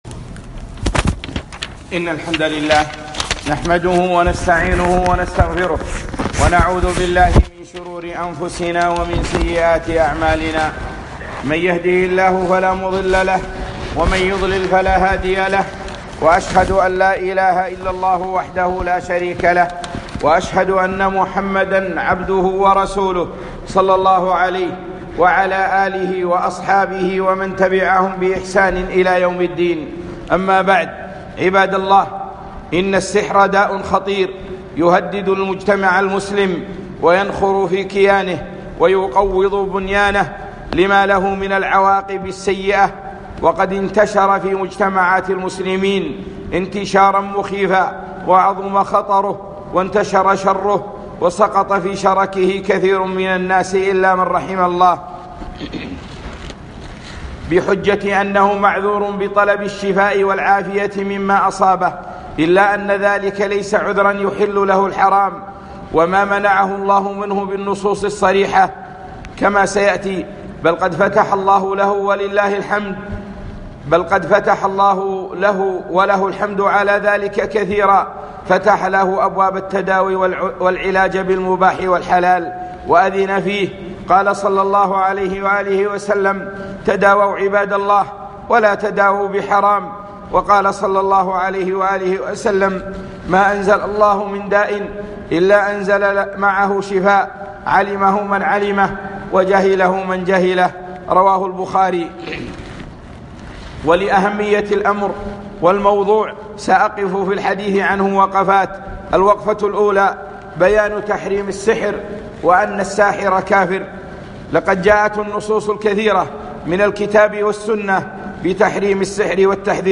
السحر - خطبة